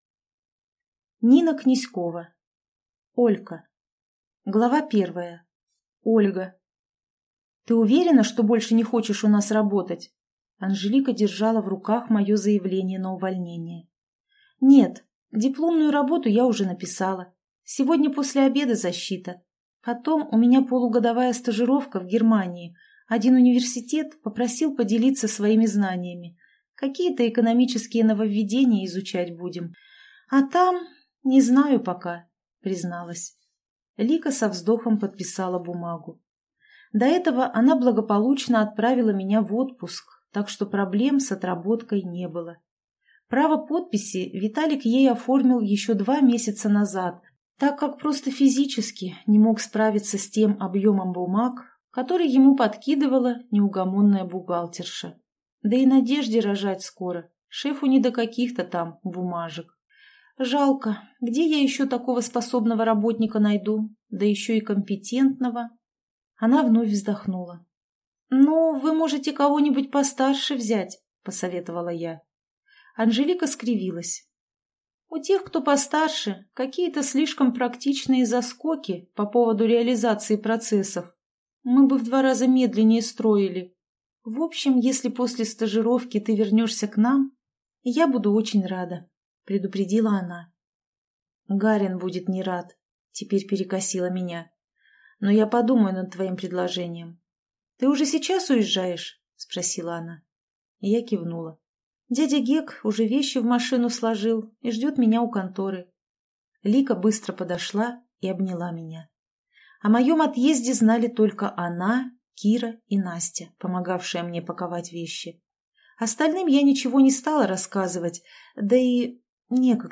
Аудиокнига Олька | Библиотека аудиокниг